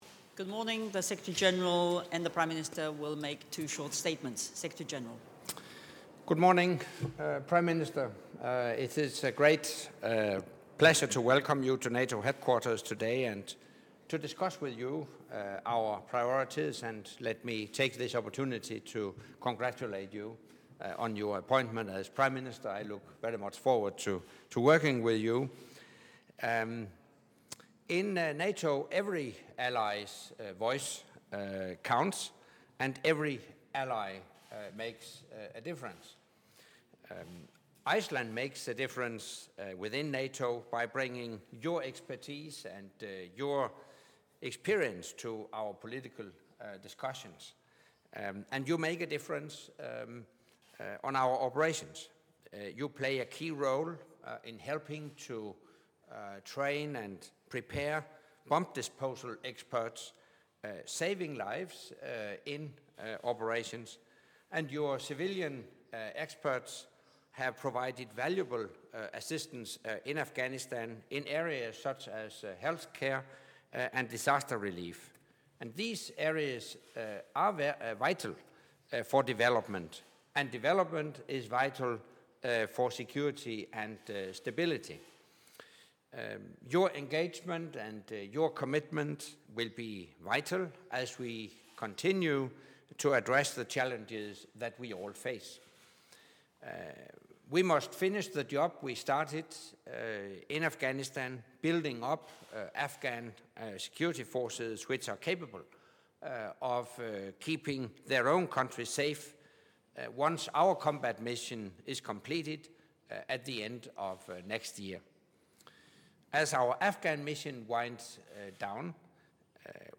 Joint press point with the Prime Minister of Iceland, Mr. Sigmundur David Gunnlaugsson and NATO Secretary General Anders Fogh Rasmussen